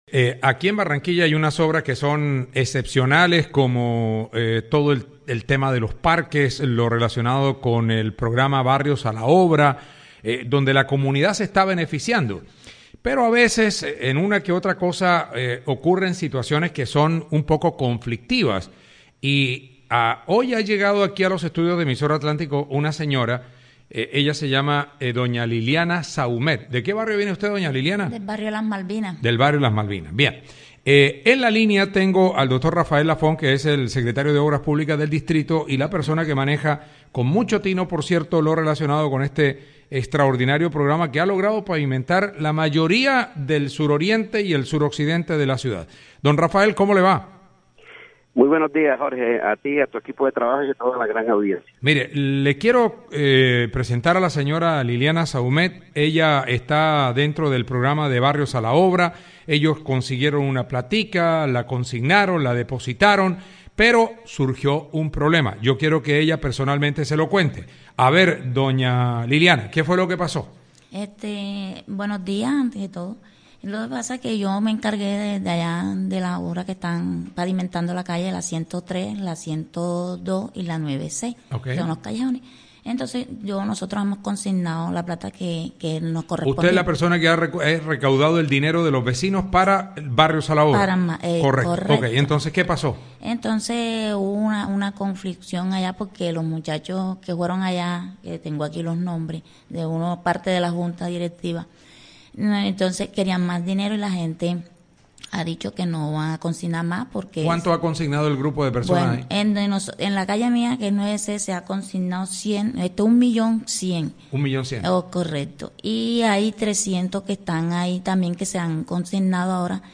El secretario de Obras Públicas del Distrito, Rafael Lafont, advirtió que la comunidad no debe entregarle dinero a personas particulares por la pavimentación de vías dentro del programa «Barrios a la Obra».